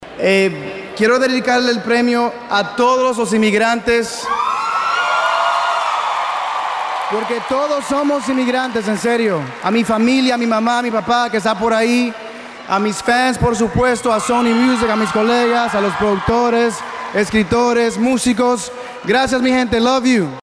AUDIO Prince Royce dedica Premio Lo Nuestro 2017 a inmigrantes